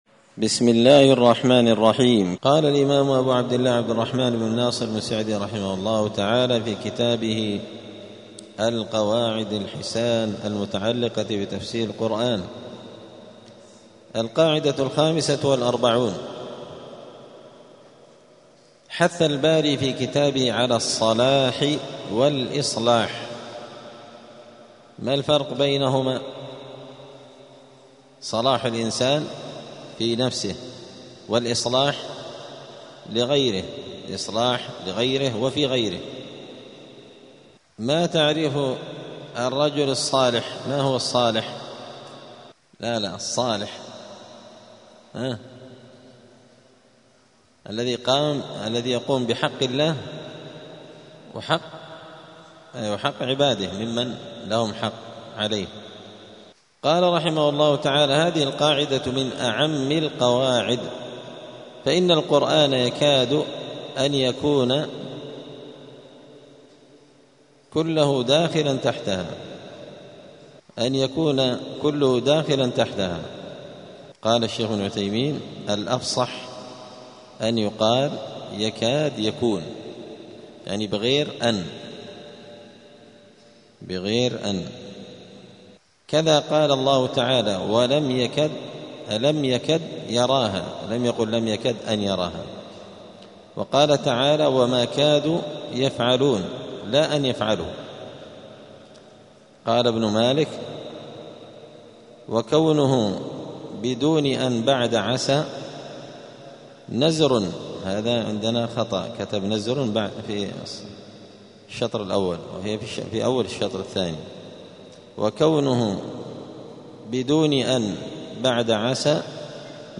دار الحديث السلفية بمسجد الفرقان قشن المهرة اليمن
59الدرس-التاسع-والخمسون-من-كتاب-القواعد-الحسان.mp3